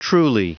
Prononciation du mot truly en anglais (fichier audio)
Prononciation du mot : truly